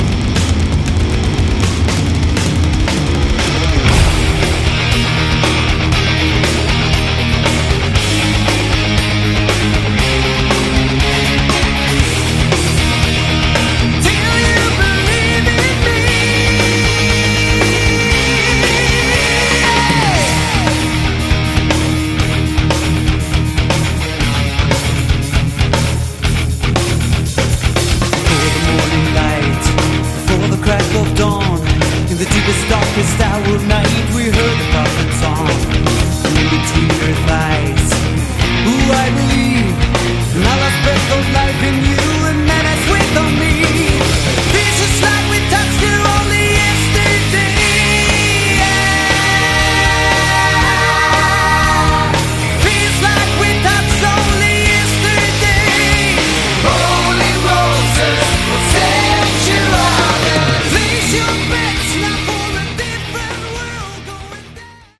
Category: AOR
lead and backing vocals
rhythm guitars, keyboards
bass, fretless bass, backing vocals
drums, percussion